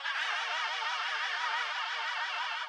GFunk.wav